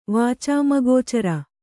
♪ vācāmagōcara